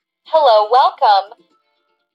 Tiếng loa thông báo Hello Welcome…
Thể loại: Tiếng chuông, còi
Description: Tải tiếng loa thông báo “Hello, Welcome” giọng nữ mp3 với âm sắc nhẹ nhàng, trong trẻo, phù hợp cho nhà hàng, khách sạn, quán cà phê. Âm thanh từ loa phát thanh, loa chào mừng, chuông lễ tân, âm báo tiếp khách, loa hướng dẫn tự động tạo cảm giác chuyên nghiệp và thân thiện...
tieng-loa-thong-bao-hello-welcome-www_tiengdong_com.mp3